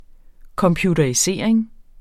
Udtale [ kʌmpjudʌɐ̯iˈseˀɐ̯eŋ ]